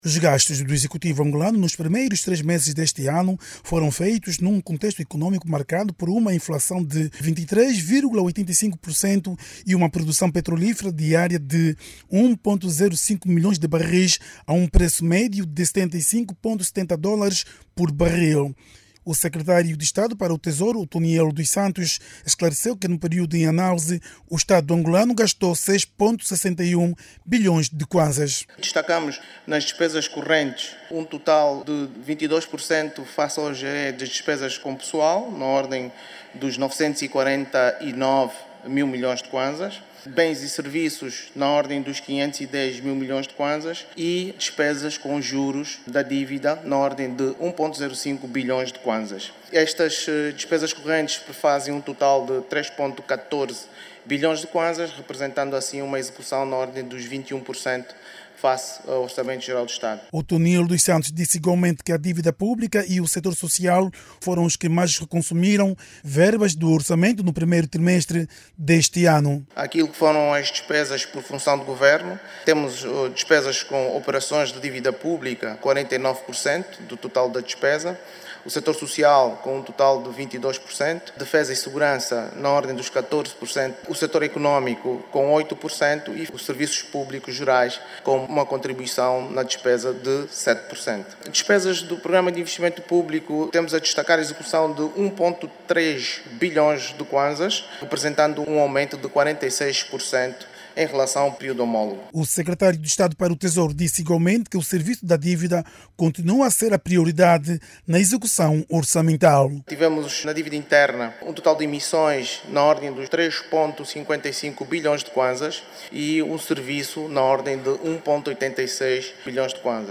O Governo afirmou que metade do dinheiro gasto no primeiro trimestre deste ano foi destinada ao serviço da dívida pública. De acordo com o relatório de execução do OGE referente ao primeiro trimestre de 2025, o sector social foi segundo que mais se beneficiou dos gastos públicos no período em referência. Clique no áudio abaixo e ouça a reportagem